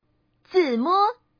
Index of /client/common_mahjong_tianjin/mahjonghntj/update/1309/res/sfx/changsha/woman/